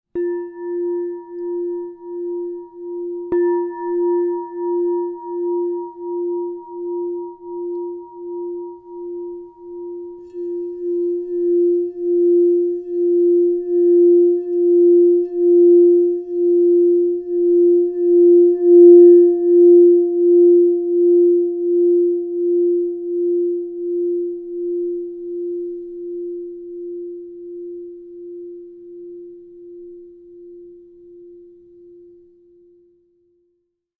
Rose Quartz, Platinum 6″ F +5 Crystal Tones singing bowl
The 6-inch size delivers focused, resonant tones, making it ideal for personal meditation, sound therapy, and sacred rituals.
Transform your practice with 6″ Crystal Tones® Rose Quartz Platinum True Tone alchemy singing bowl in the key of F +5.
+5 (True Tone)
440Hz (TrueTone), 528Hz (+)